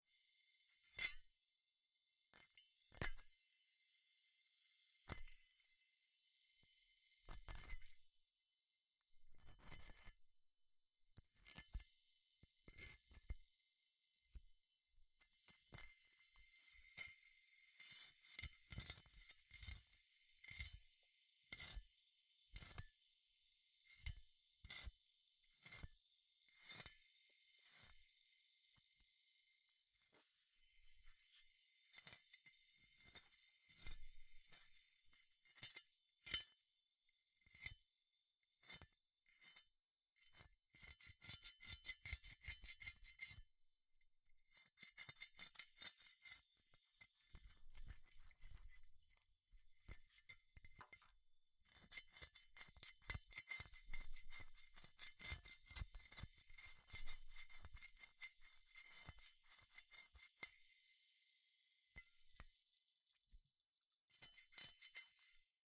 Здесь вы можете скачать или послушать онлайн странные шумы, шаги и другие аудиофрагменты.
Звук царапания двери домовым кошкенем